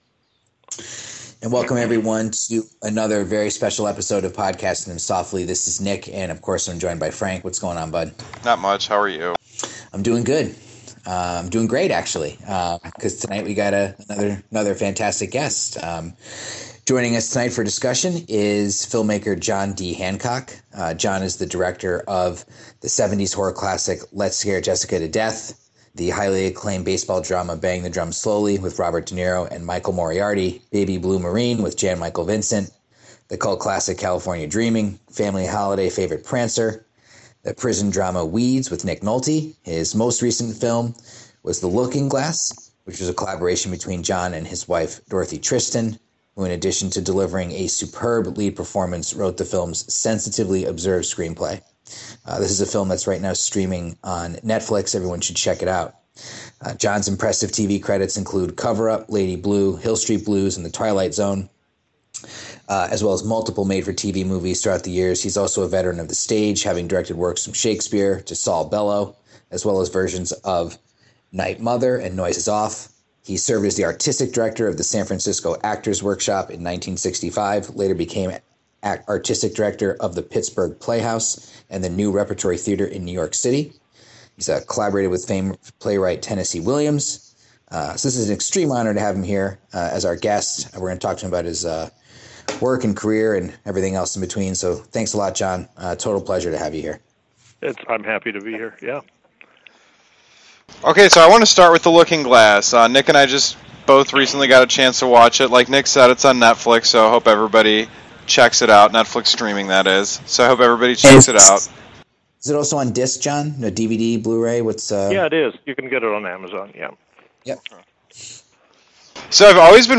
Podcasting Them Softly is thrilled to present a discussion with filmmaker John D. Hancock!